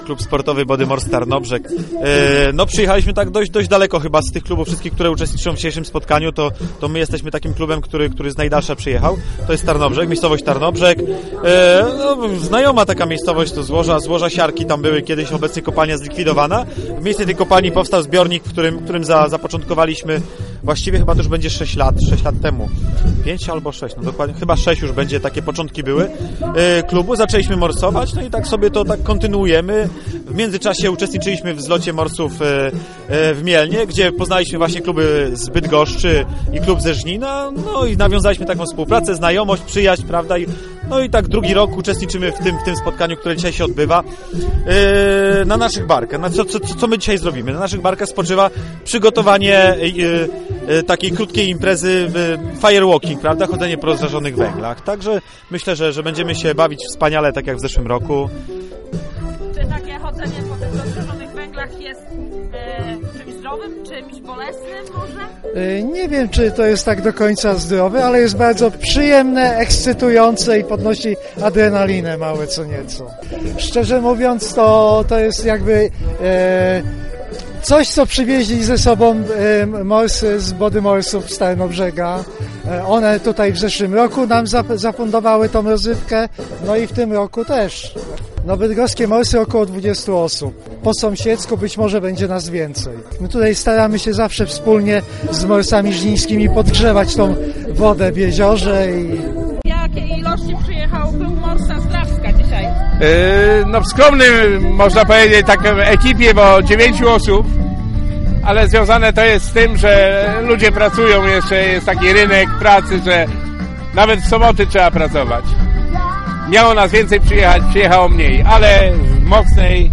5 grudnia nad Jeziorem Małym Żnińskim odbył się II Ogólnopolski Zlot Morsów pod hasłem "Mikołaj Trzyma Formę".